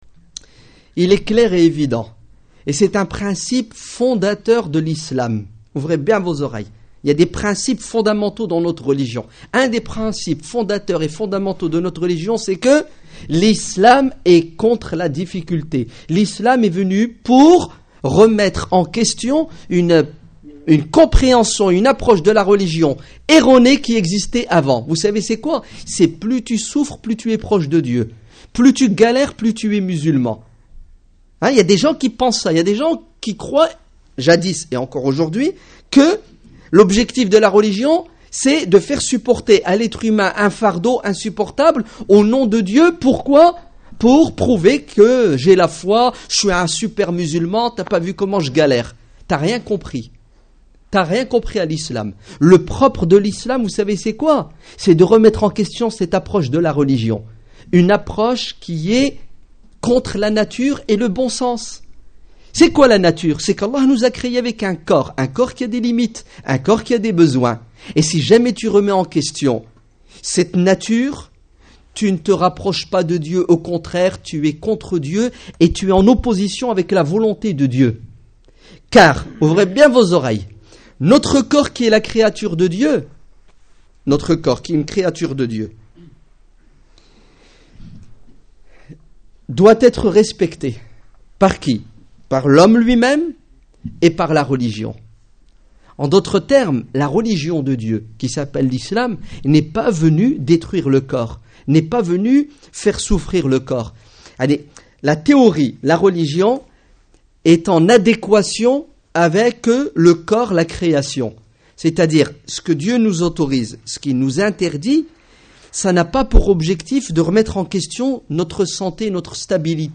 Discours du vendredi